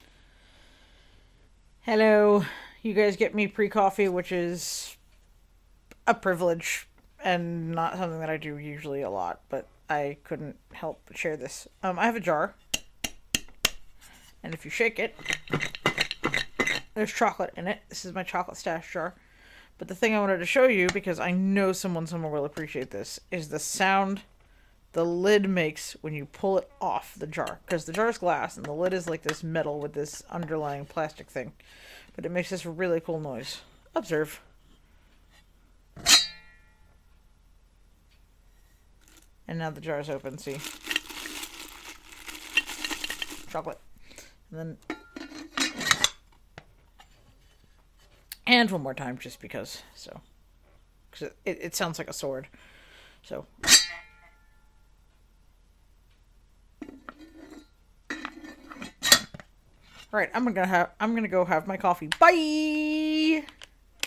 tempo: 300